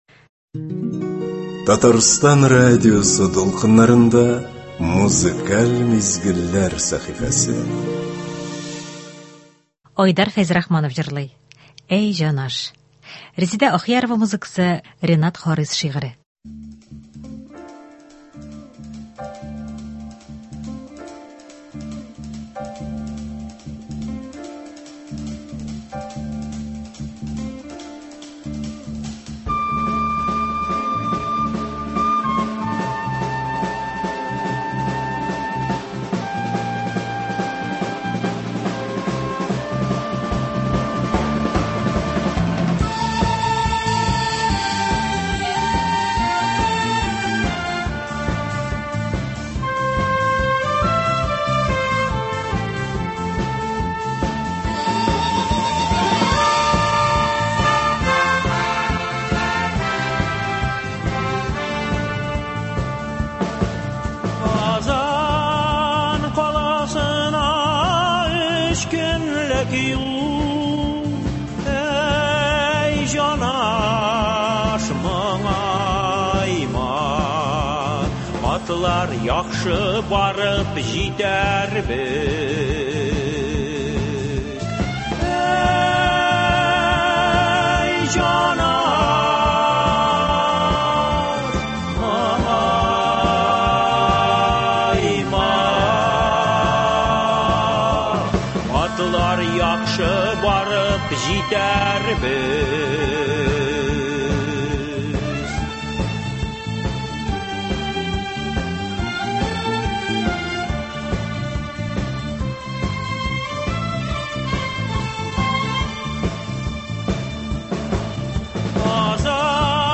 Иртәбезне, гадәттәгечә, моңлы җырлар белән башлап җибәрик.